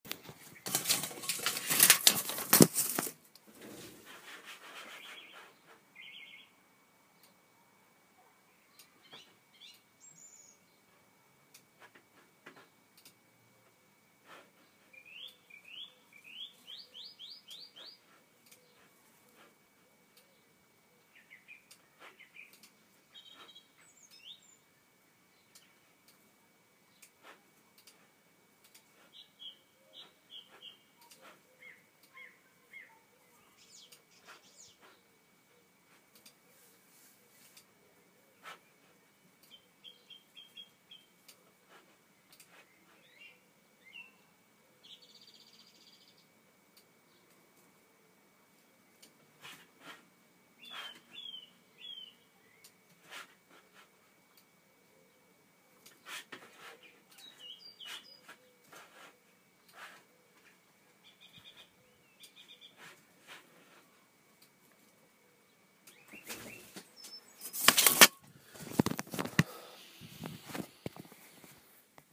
Bird Song - Car Alarm